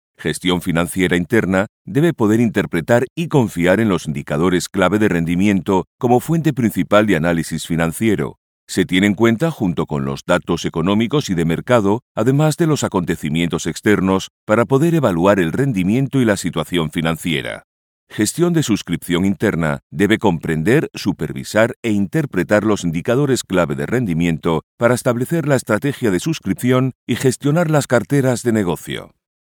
Corporate 2